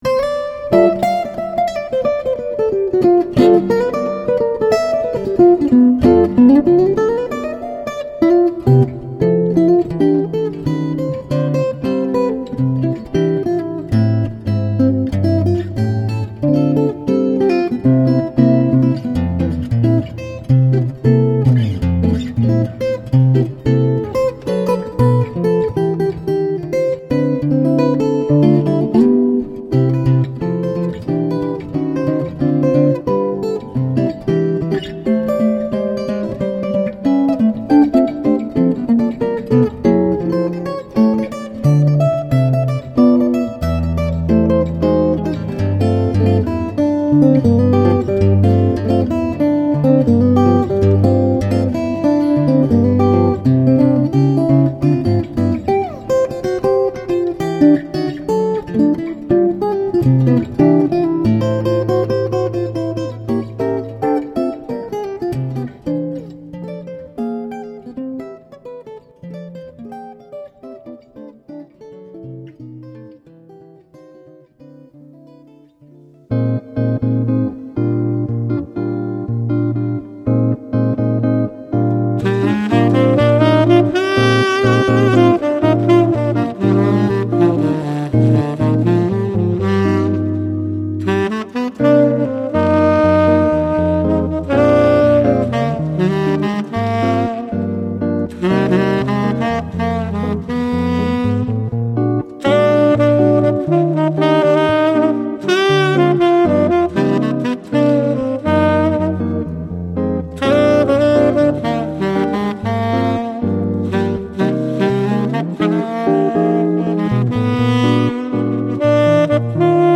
Sologitarre mit musikalischen Dialogen verschiedenster Art.
Bossa Nova, Uptempo Bebop, Jazz Blues und Balladen vereint.